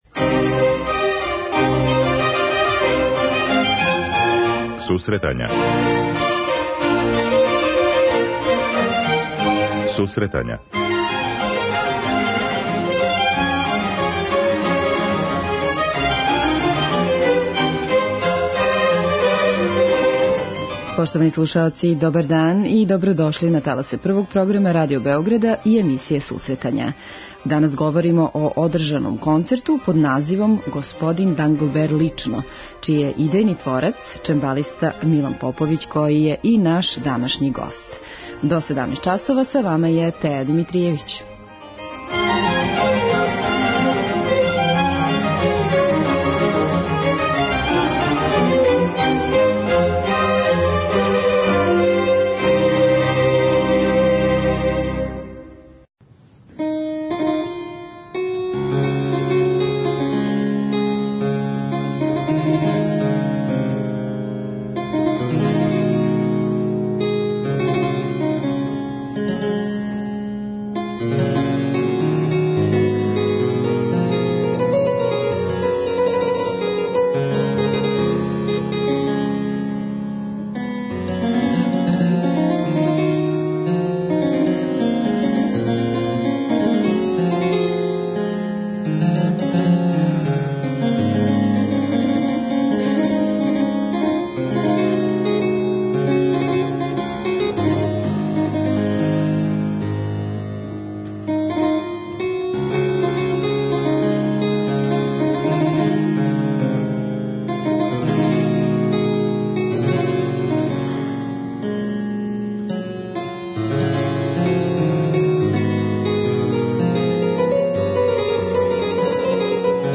преузми : 10.78 MB Сусретања Autor: Музичка редакција Емисија за оне који воле уметничку музику.